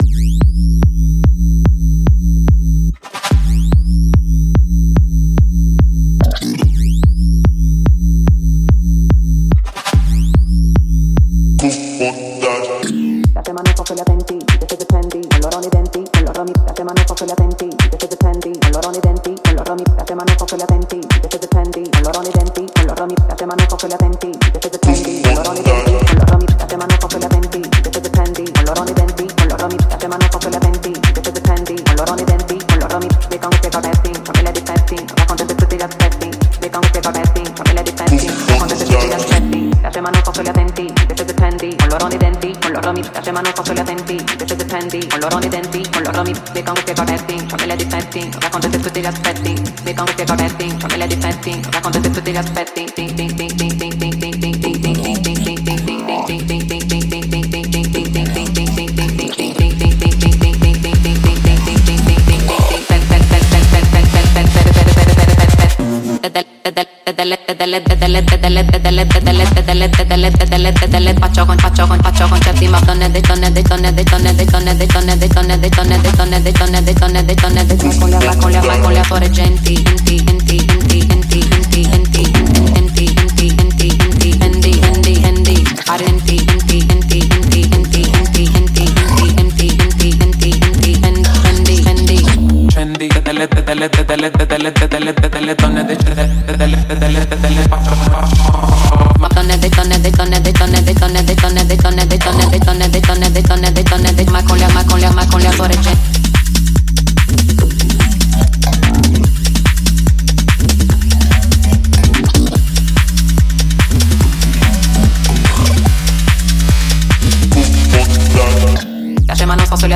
электроника, трип